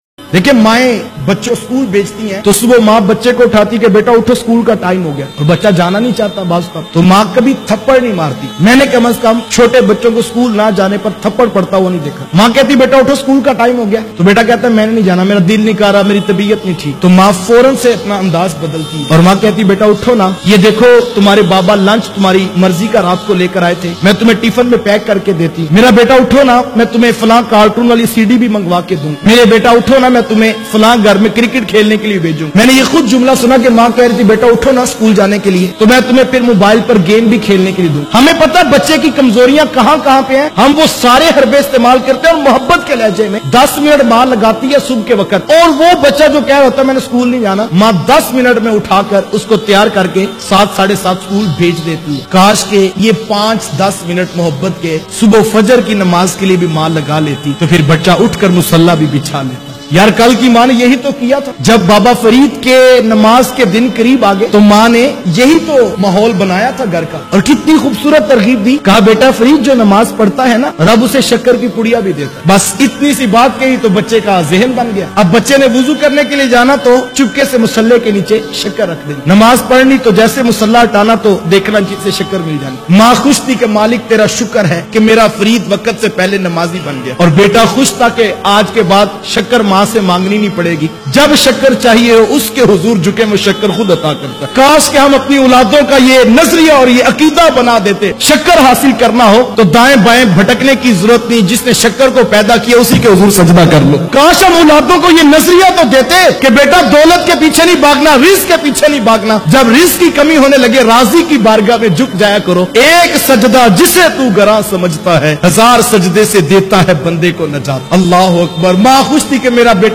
Namaz ka hukam bacho k liay bayan mp3